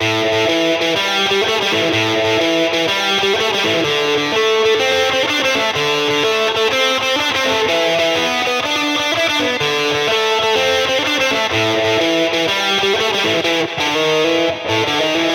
Tag: 125 bpm Blues Loops Guitar Electric Loops 2.58 MB wav Key : Unknown